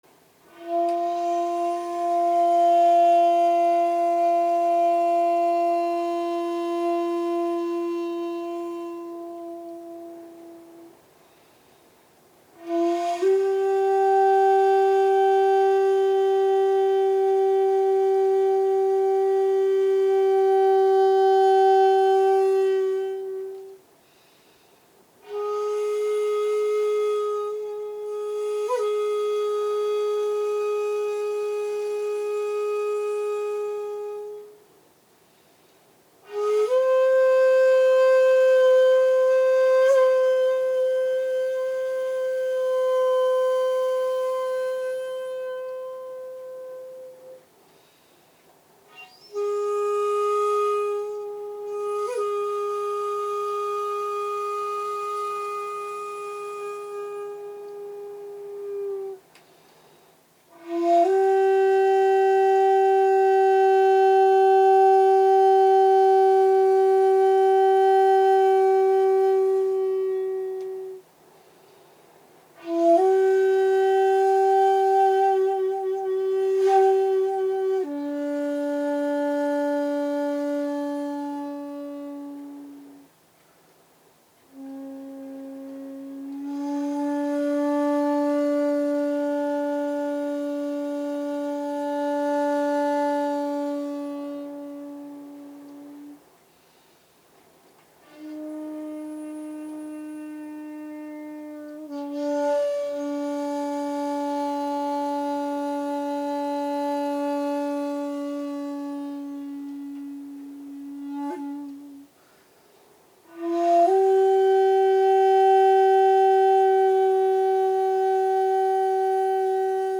さっそく吹奏させていただくと、一見単純そうに見えますが音楽的によく考えられた本曲です。
しかも「虚吹」という装飾を極力さけた吹き方で奏者の技量が明白に現れます。